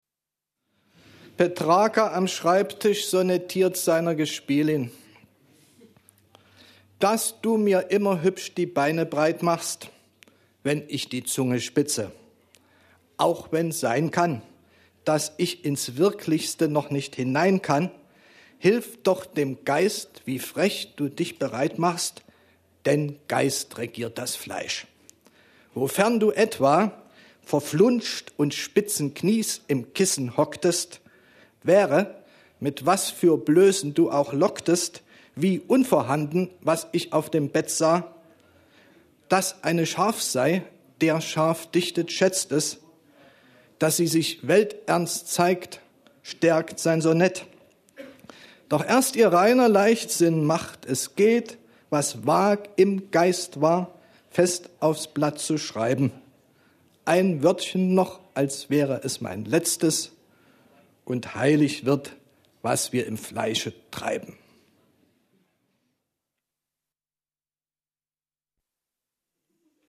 Lesung von Rainer Kirsch in der literaturWERKstatt Berlin zur Sommernacht der Lyrik – Gedichte von heute